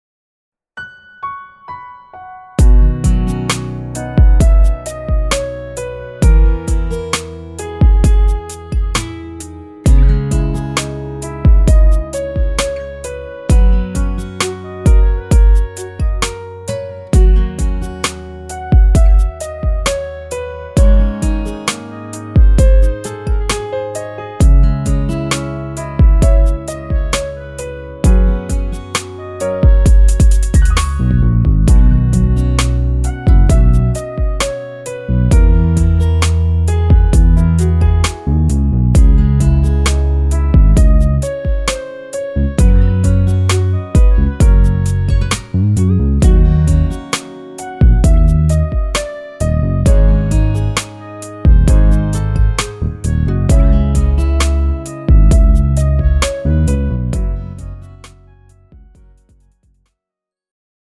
장르 가요 구분